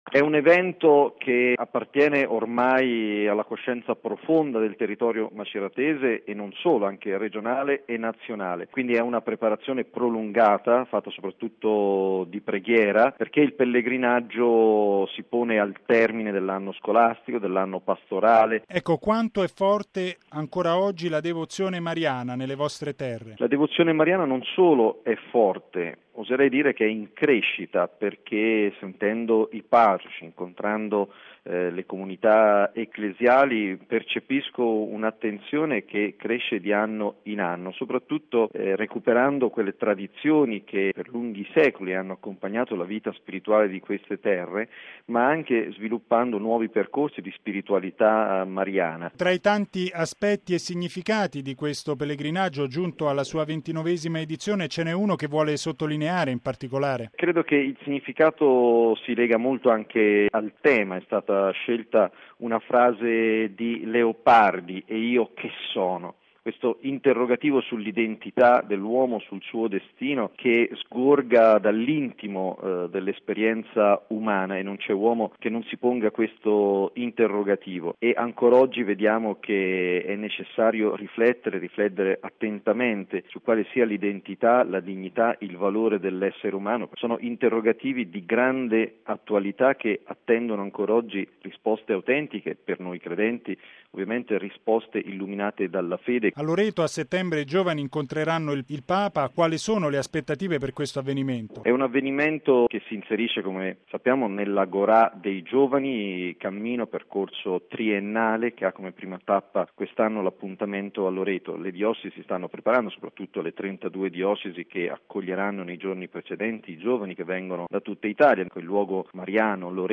Il vescovo di Macerata, mons. Claudio Giuliodori, presenta ai nostri microfoni il pellegrinaggio mariano Macerata-Loreto